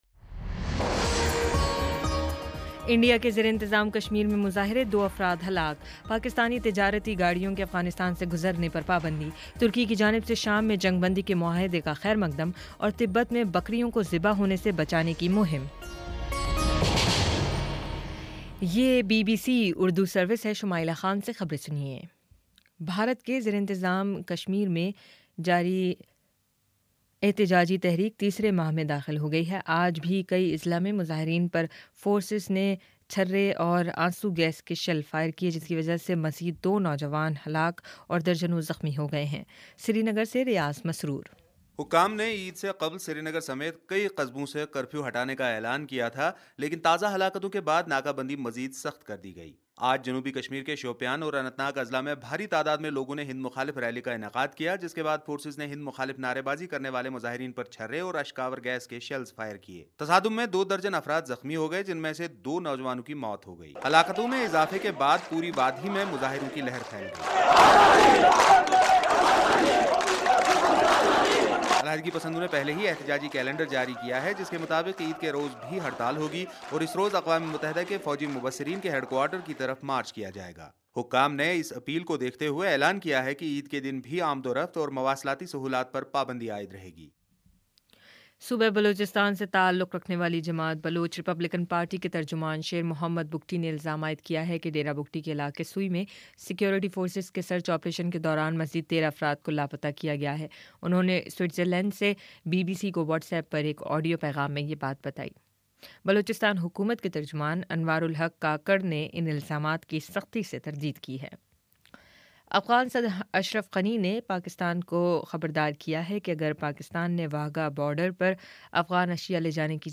ستمبر 10 : شام سات بجے کا نیوز بُلیٹن